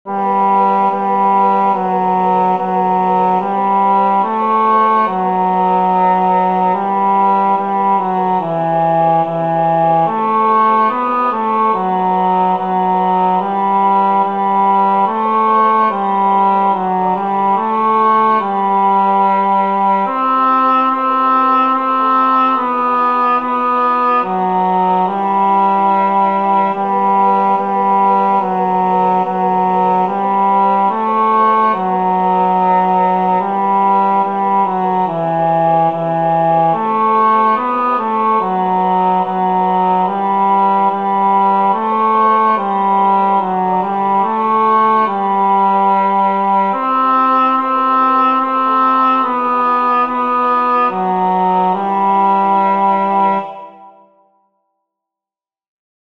El tempo indicado es Calmo, negra= 72.